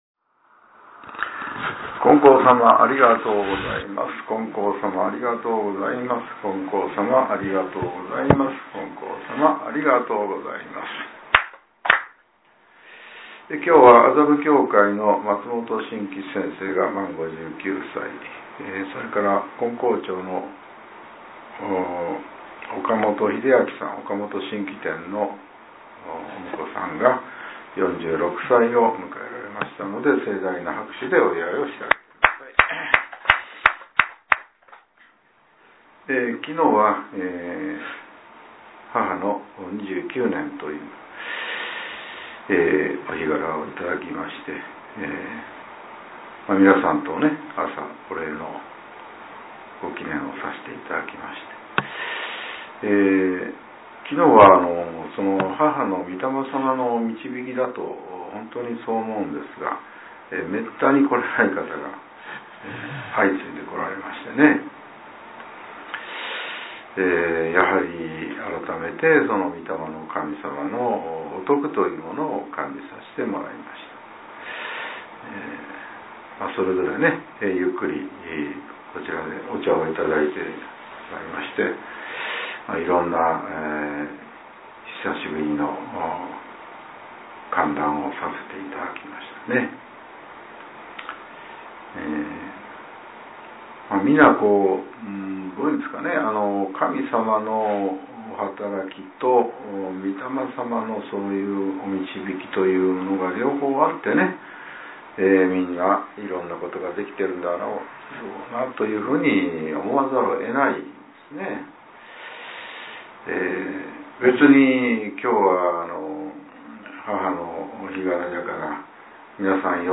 令和７年２月２５日（朝）のお話が、音声ブログとして更新されています。